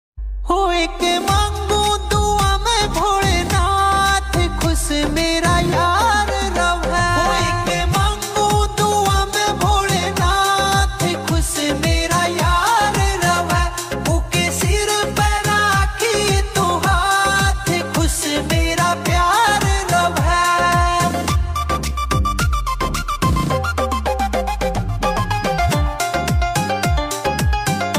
Punjabi Songs
uplifting track
This vibrant melody